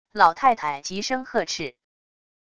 老太太急声喝斥wav音频